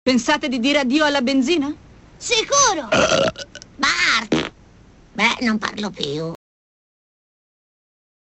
Bart: rutto e scorreggia
bruttopuzza.mp3